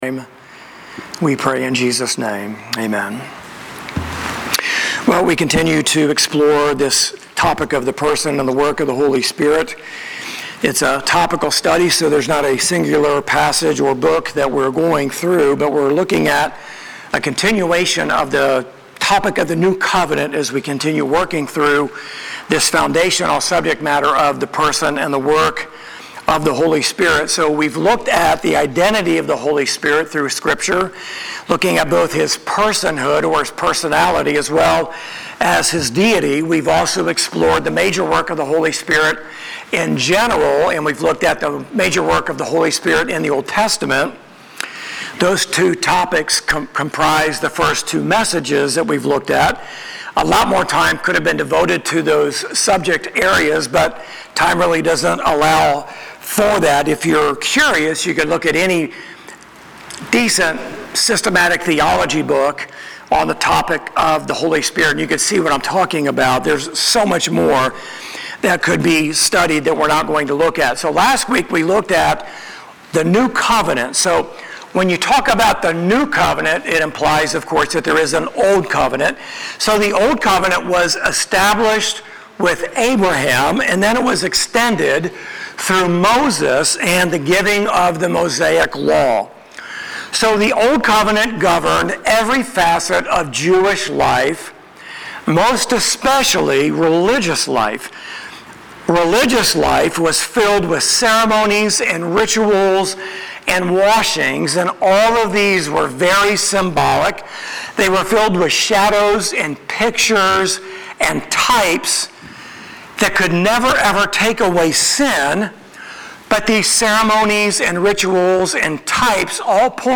Sermon-8-18-25.mp3